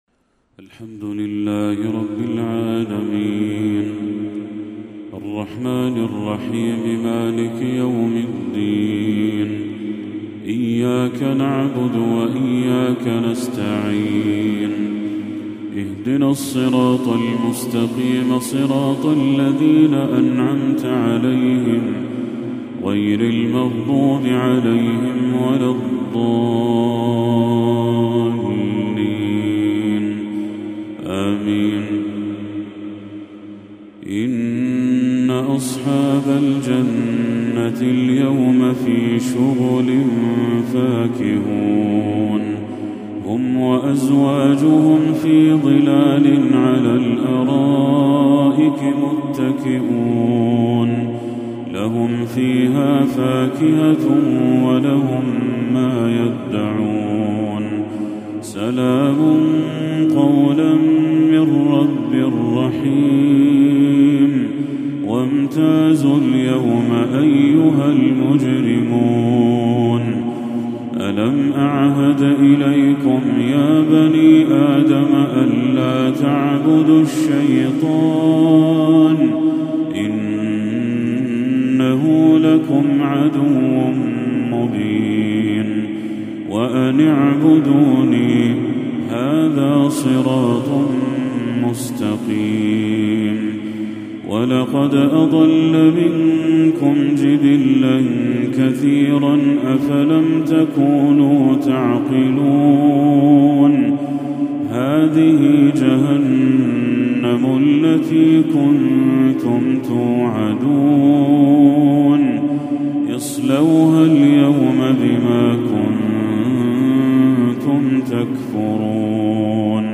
تلاوة شجيّة ماتعة لخواتيم سورة يس للشيخ بدر التركي | عشاء 14 ربيع الأول 1446هـ > 1446هـ > تلاوات الشيخ بدر التركي > المزيد - تلاوات الحرمين